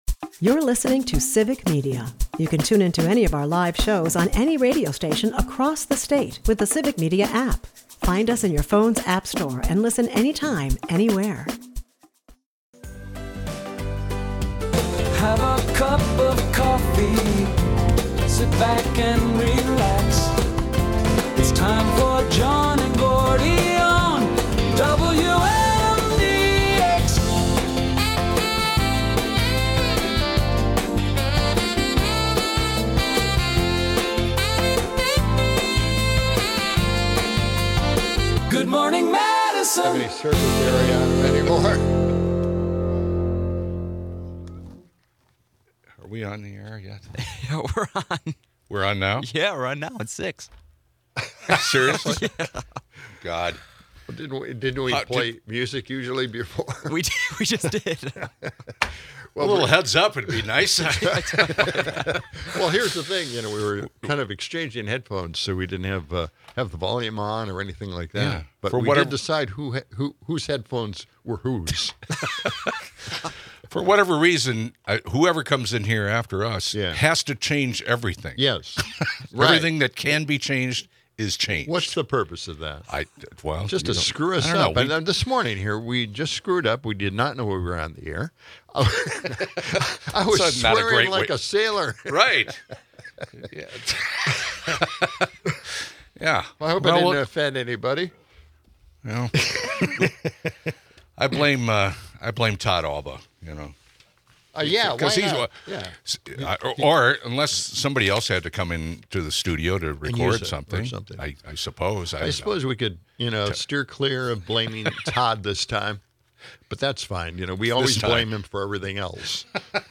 In a chaotic start, hosts grapple with audio mishaps and stir up Halloween nostalgia, reminiscing about wild State Street celebrations and pitching a revival.
Amidst lighthearted banter about costumes and Cubs, there's serious discourse on government shutdowns and fiscal policy.